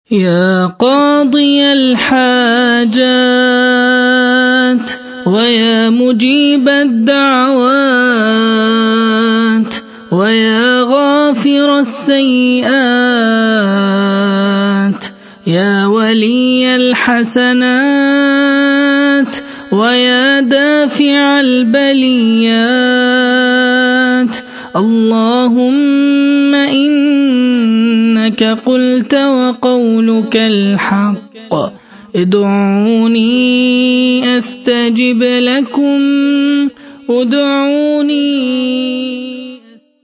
ادعية